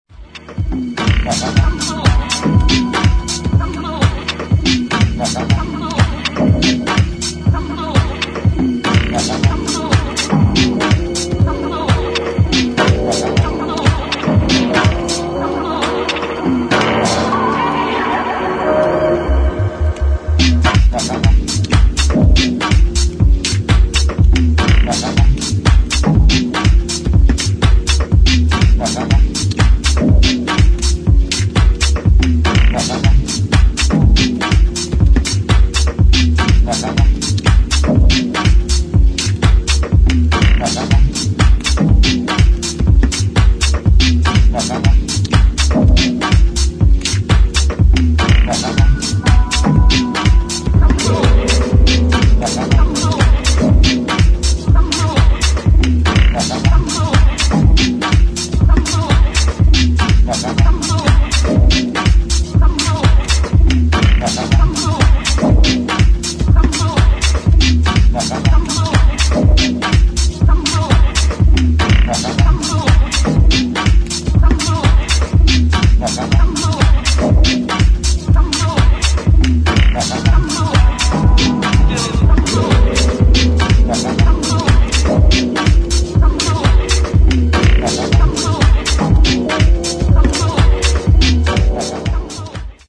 [ DEEP HOUSE ]